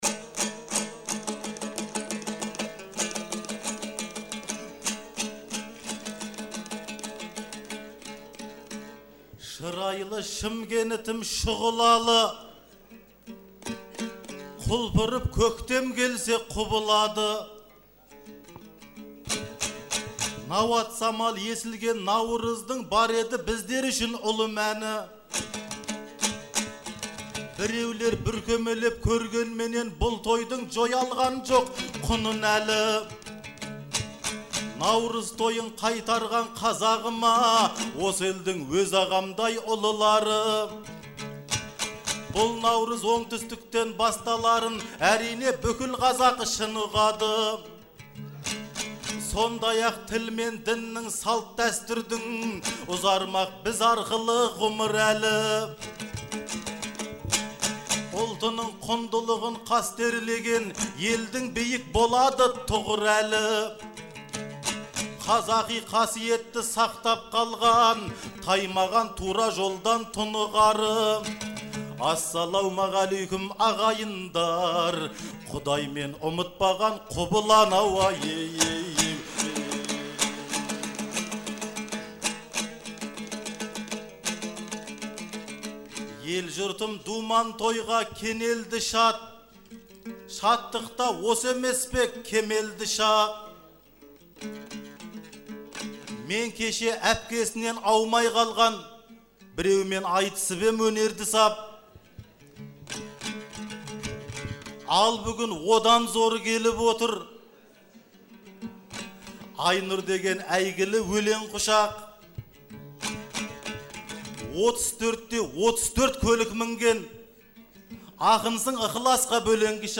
Наурыздың 15-і мен 16-сы күні Шымкент қаласында «Наурыз айтысы» өтті.